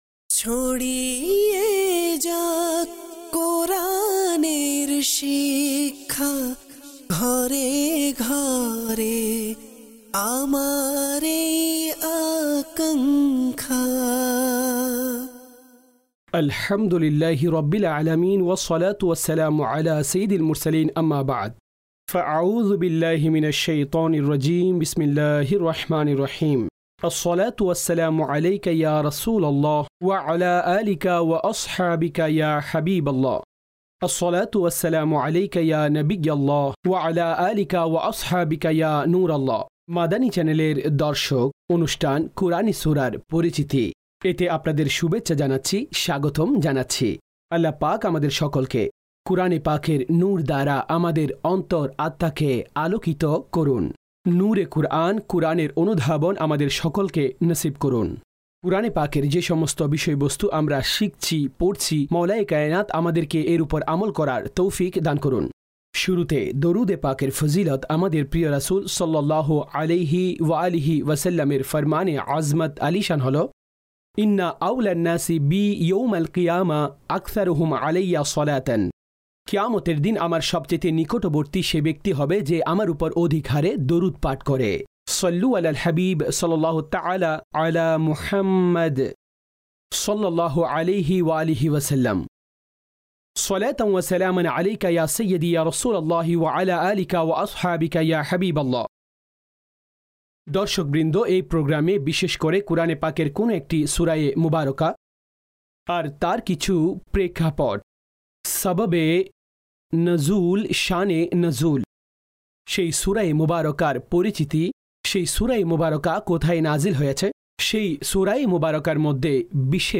কুরআনি সূরার পরিচিতি (বাংলায় ডাবিংকৃত) EP# 29